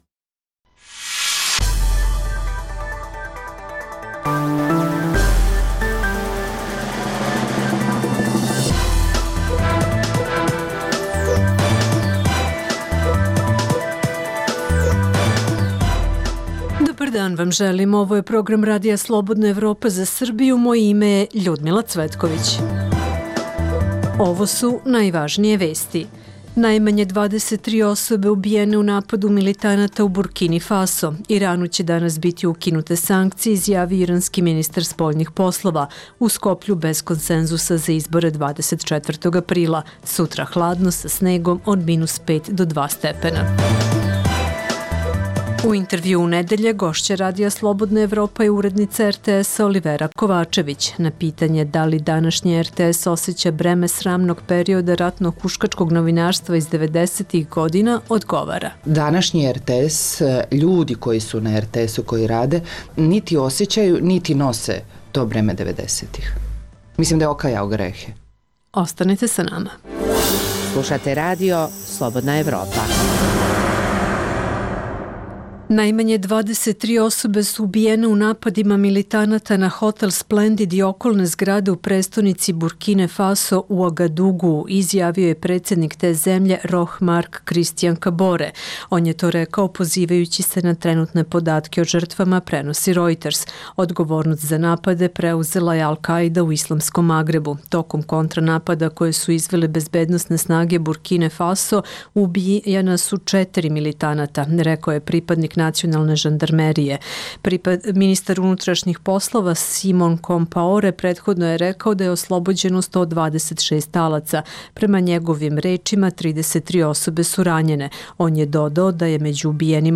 Pored dnevnih aktuelnosti slušaćete Intervju nedelje.